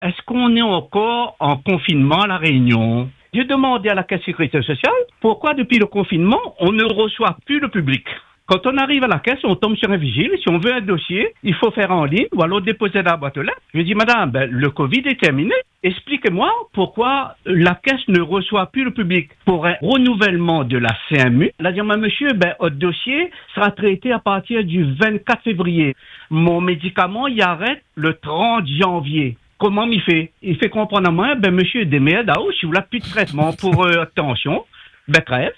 Il nous a appelés pour poser une question simple, mais essentielle :